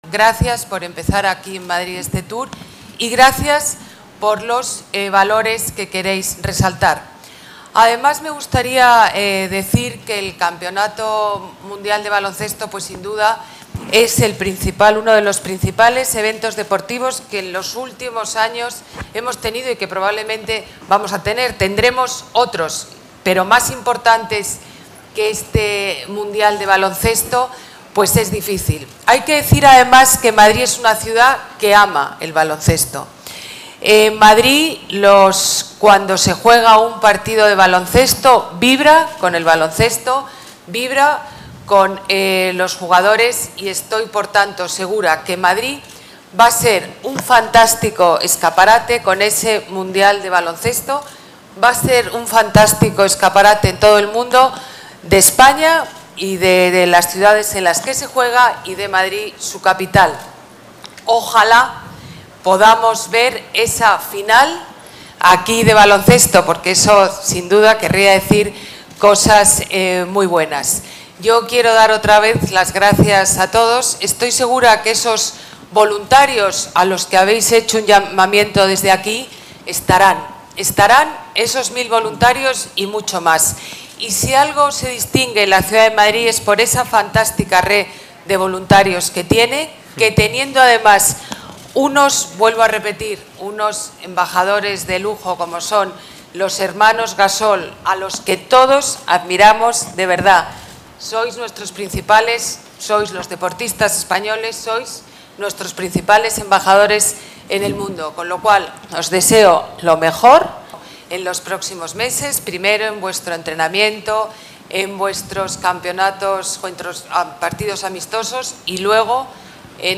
Nueva ventana:Declaraciones de la alcaldesa, Ana Botella